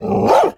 Minecraft Version Minecraft Version 1.21.5 Latest Release | Latest Snapshot 1.21.5 / assets / minecraft / sounds / mob / wolf / angry / bark2.ogg Compare With Compare With Latest Release | Latest Snapshot
bark2.ogg